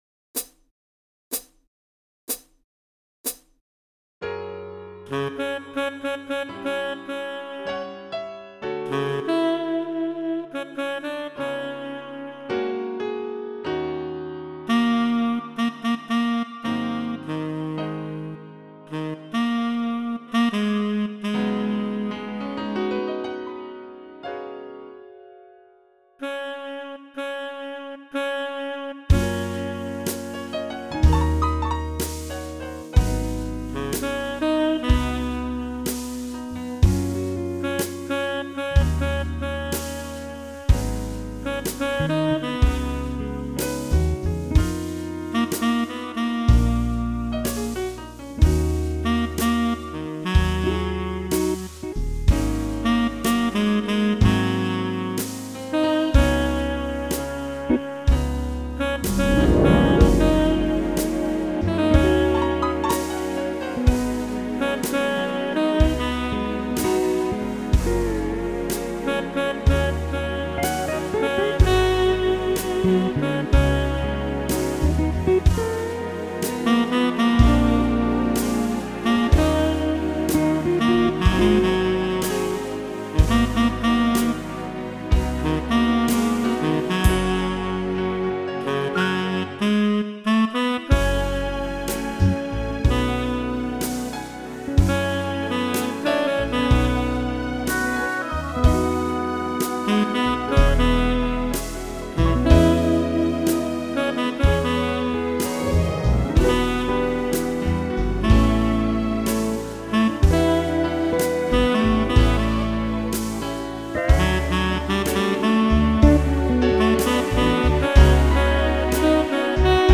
Jazzy piano and lush violins beat “bubblegum” every time.